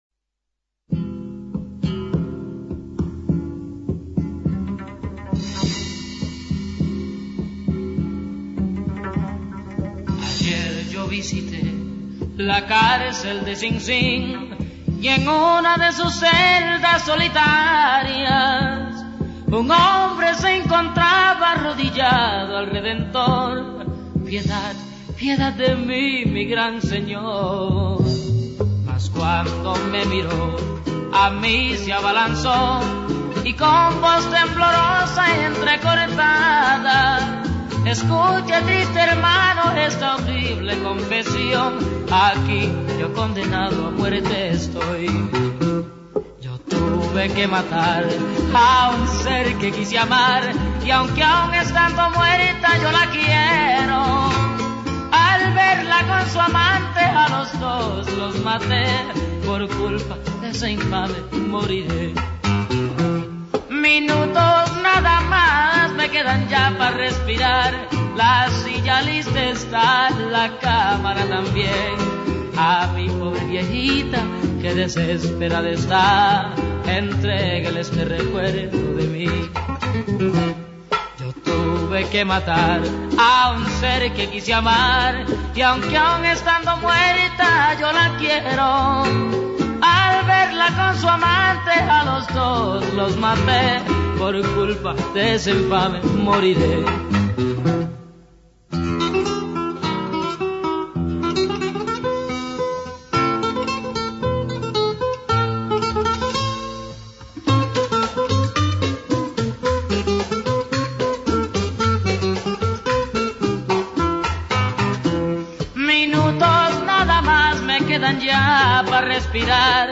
Hablamos del escritor norteamericano John Cheever, de su novela "Falconer" (1977). Leímos algunos fragmentos de esta obra que narra la experiencia de Ezequiel Farragut, un ex-profesor universitario de 48 años que es adicto a las drogas y es encarcelado por asesinar a su hermano.
Leímos fragmentos de sus "Diarios" correspondientes a aquellos días de internación en los que intentaba desintoxicarse de su adicción.